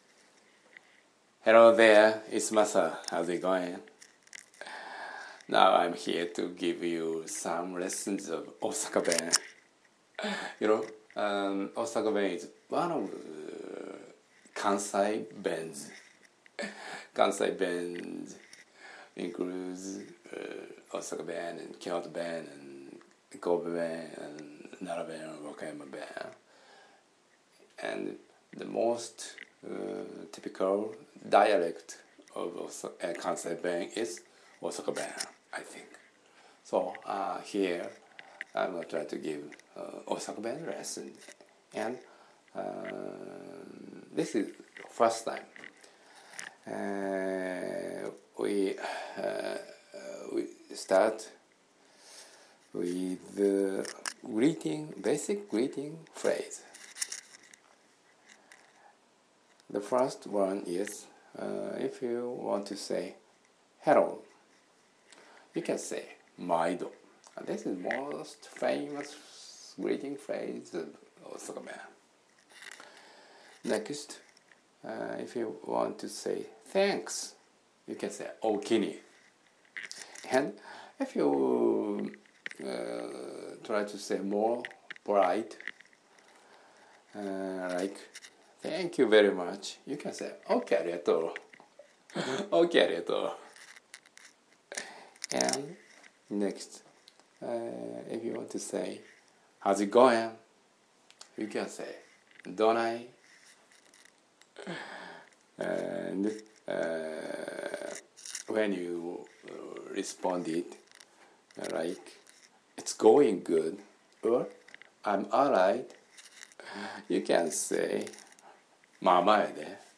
A Lesson in Osaka Dialect